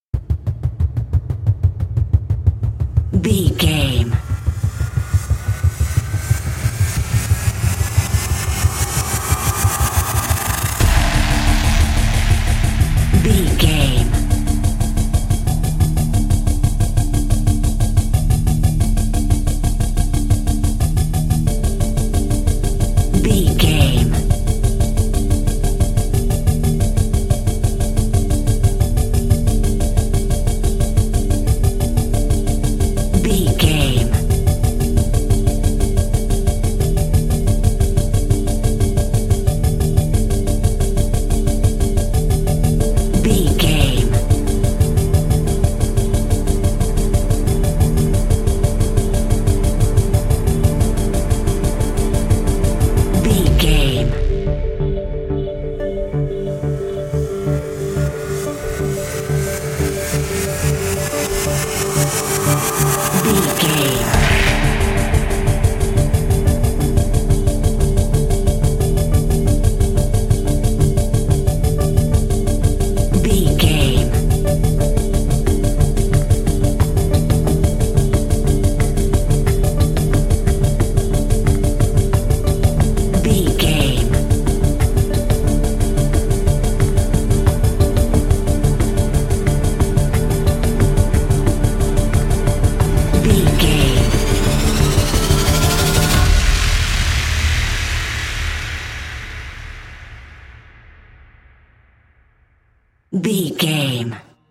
Thriller
Aeolian/Minor
drum machine
synthesiser
electric piano
percussion
ominous
creepy